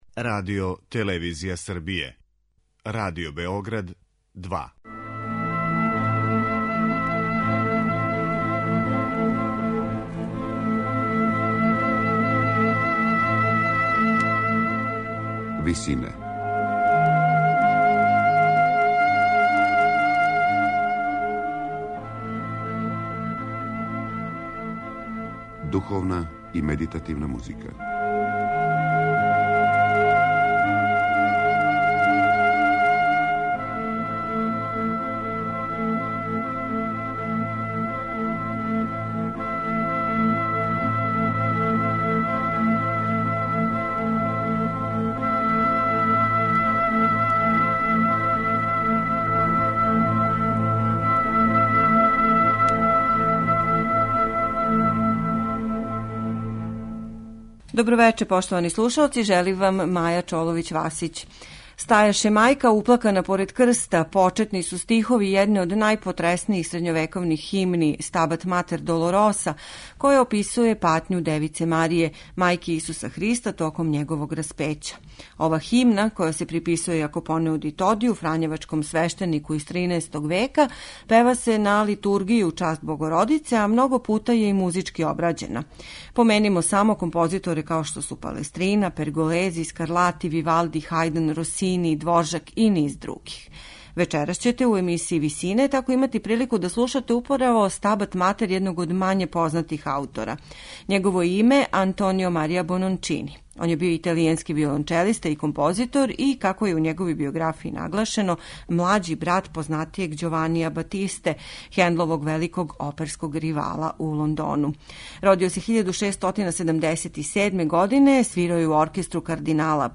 Композцицију за квартет солиста, хор и оркестар слушаћете у извођењу чланова ансамбла Кончерто италијано, чији је уметнички руководилац Риналдо Алесандрини.